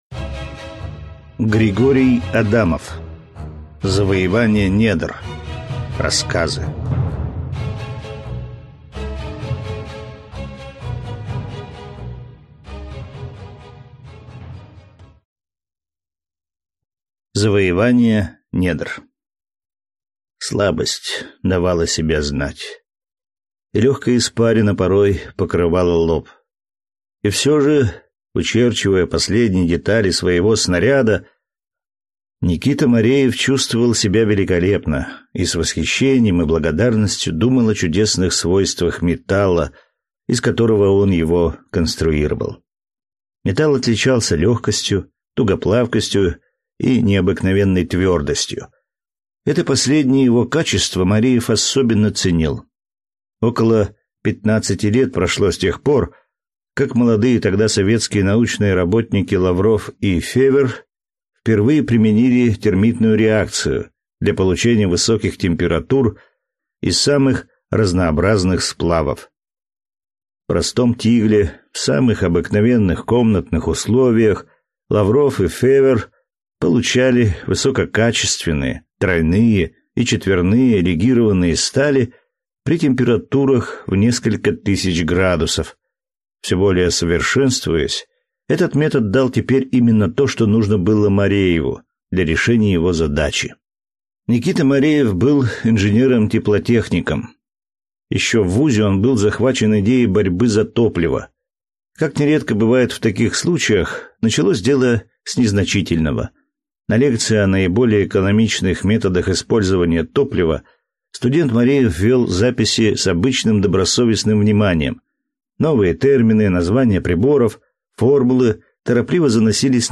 Аудиокнига Завоевание недр. Рассказы | Библиотека аудиокниг